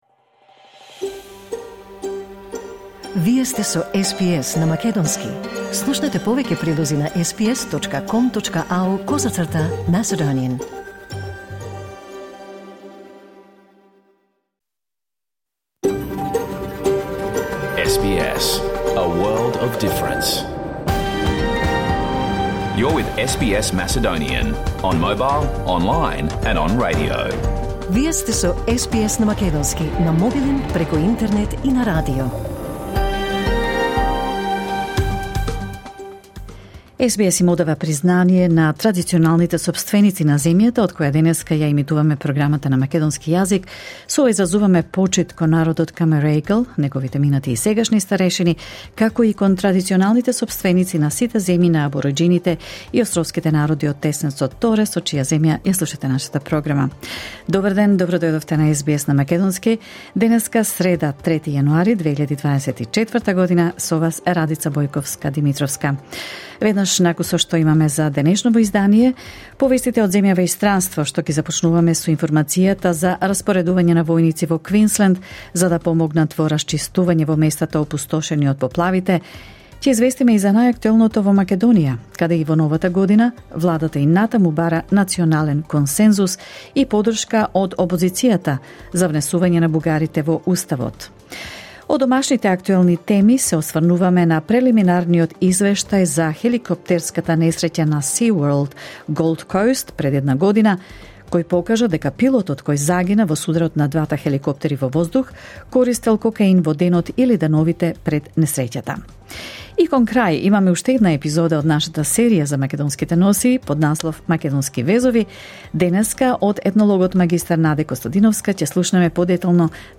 SBS Macedonian Program Live on Air 3 January 2024